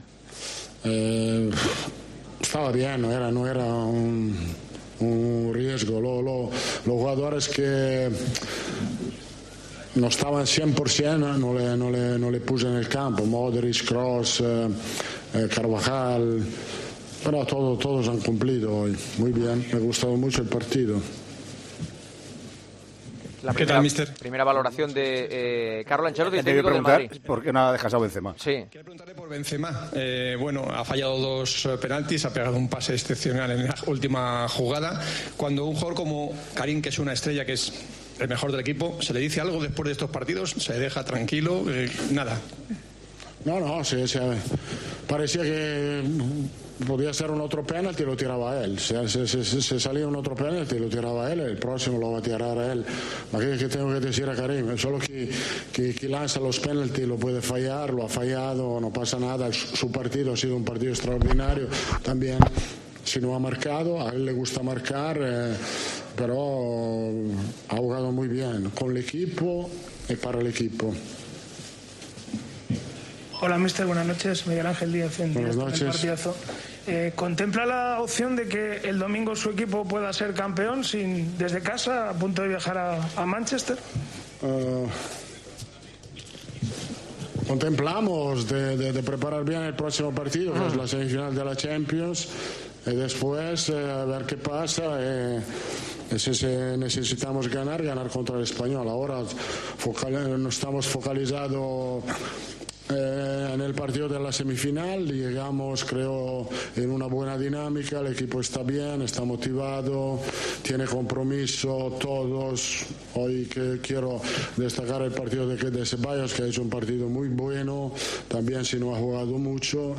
EN RUEDA DE PRENSA
El técnico italiano ha comparecido ante los medios tras el triunfo ante Osasuna y ha asegurado que "lo de Alaba no parece nada serio ni importante".